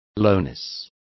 Complete with pronunciation of the translation of lowness.